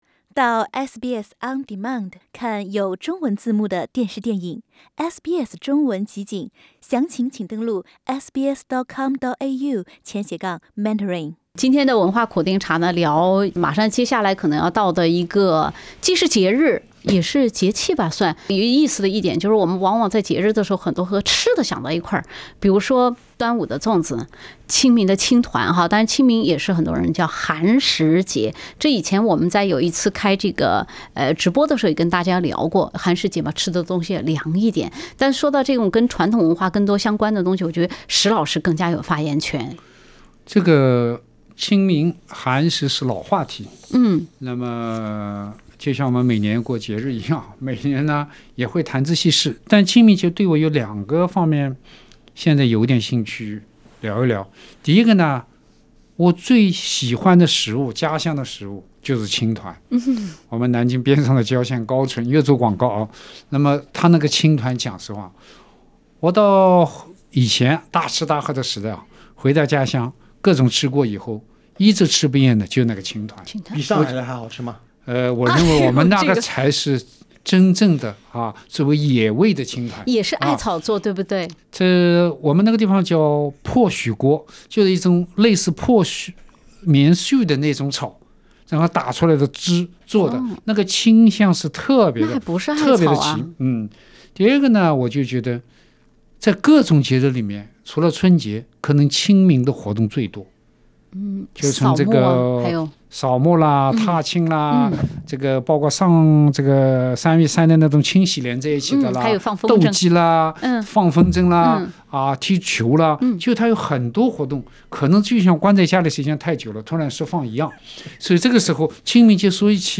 Source: Getty Images SBS 普通话电台 View Podcast Series Follow and Subscribe Apple Podcasts YouTube Spotify Download (17.96MB) Download the SBS Audio app Available on iOS and Android 端午的粽子，清明的团。